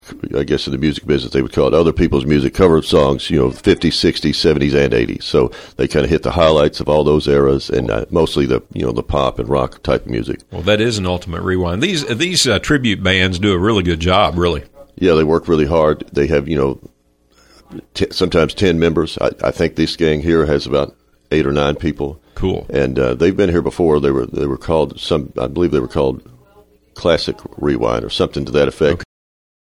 • Plaza board member says the theatre’s acoustics and events boost downtown businesses